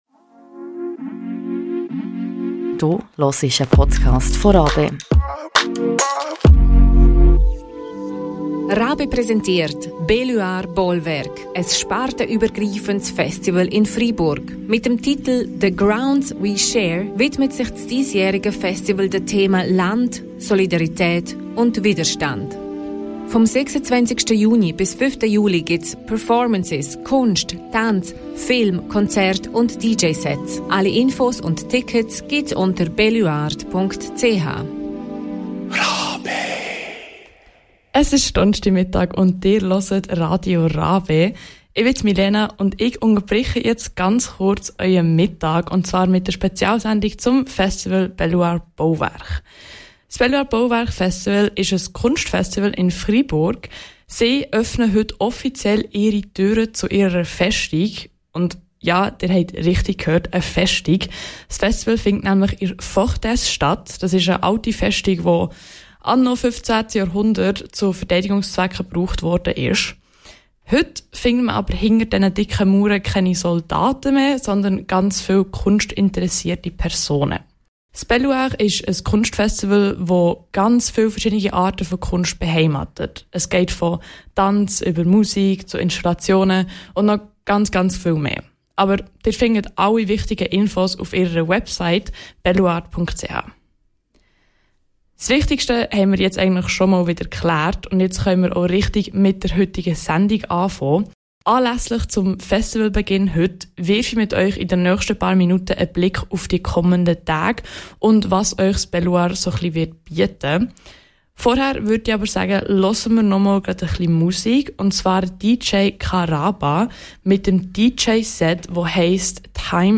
In dieser Sendung auf Radio RaBe haben wir euch einen ersten Einblick in das vielfältige Festivalprogramm gegeben. Exklusiv gab es ausserdem ein Gespräch